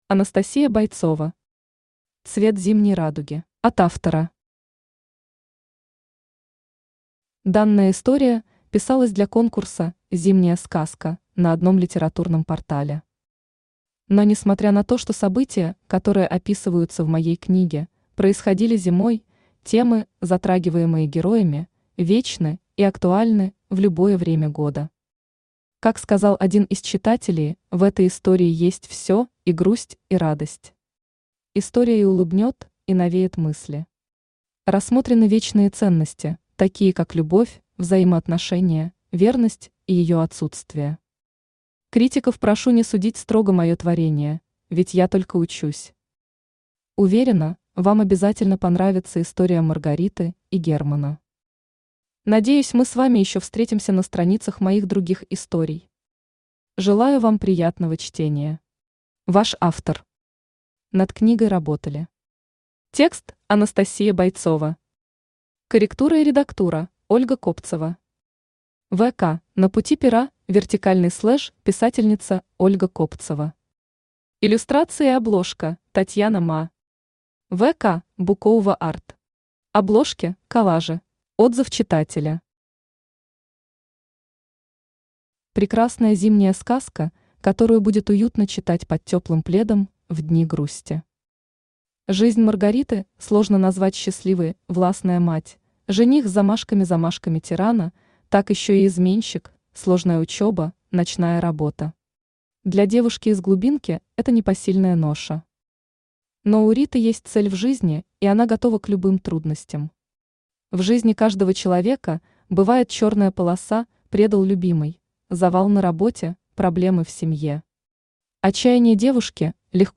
Aудиокнига Цвет зимней радуги Автор Анастасия Бойцова Читает аудиокнигу Авточтец ЛитРес.